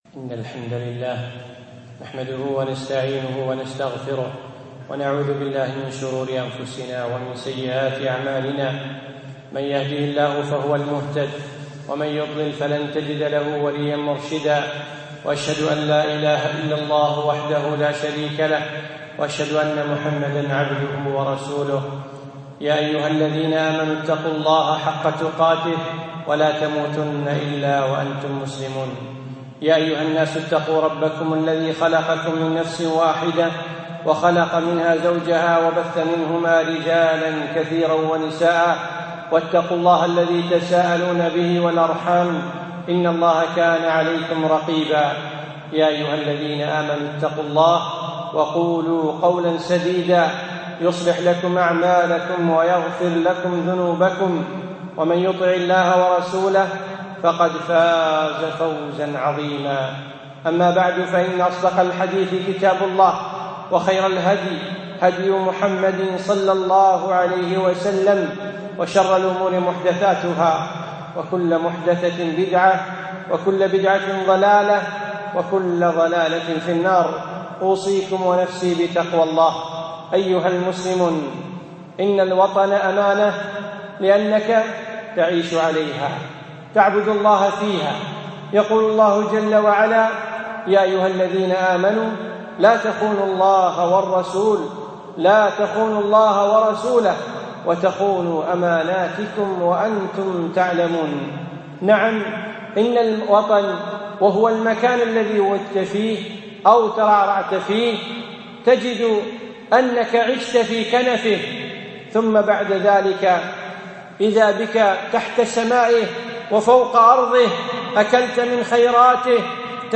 يوم الجمعة 22شوال1436هـ 7 8 2015 في مسجد عائشة المحري المسايل